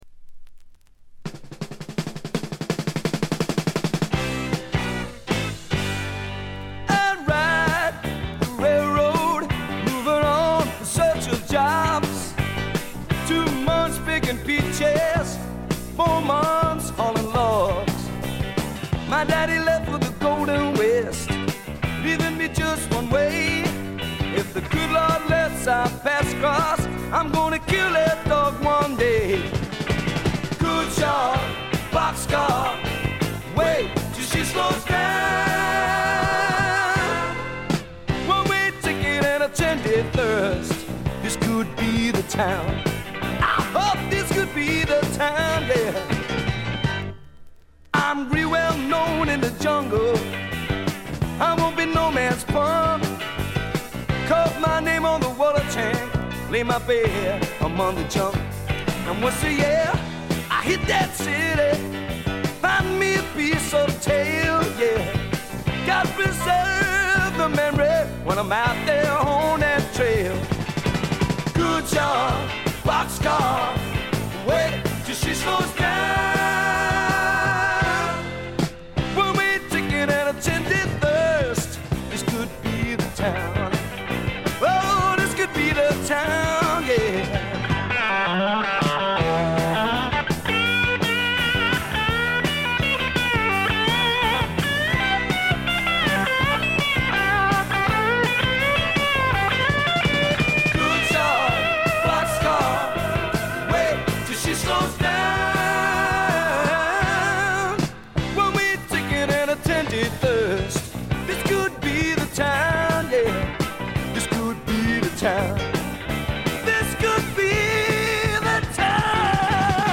静音部で軽微なチリプチ。散発的なプツ音少し。
思い切り泥臭さいサウンドなのにポップでキャッチーというのも素晴らしい。
試聴曲は現品からの取り込み音源です。